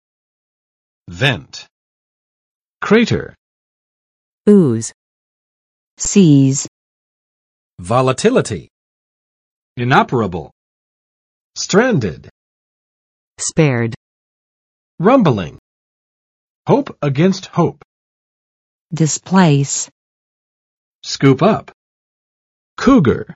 [vɛnt] n. 出口；漏孔; 火山口